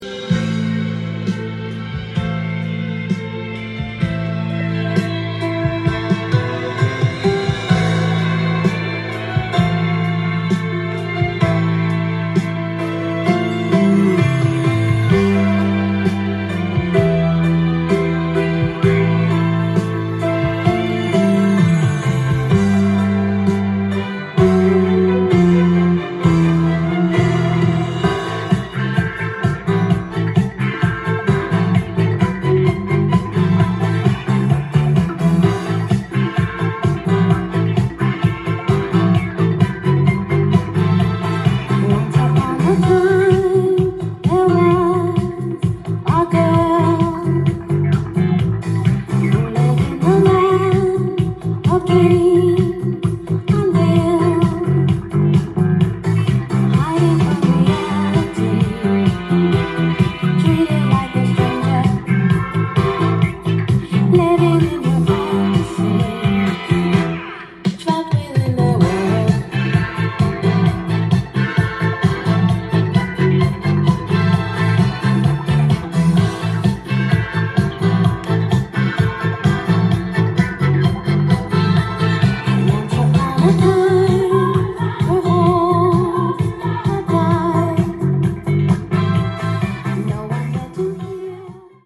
ジャンル：Soul-7inch-全商品250円
店頭で録音した音源の為、多少の外部音や音質の悪さはございますが、サンプルとしてご視聴ください。
音が稀にチリ・プツ出る程度